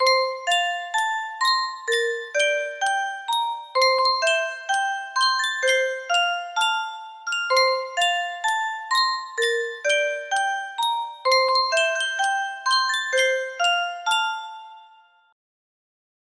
Yunsheng Music Box - Five Little Ducks 4830 music box melody
Full range 60